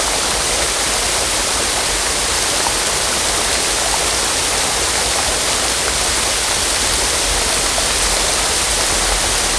waterfall4.wav